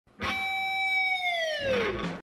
Play Eric Andre Guitar Riff - SoundBoardGuy
Play, download and share Eric Andre Guitar Riff original sound button!!!!
eric-andre-guitar-riff.mp3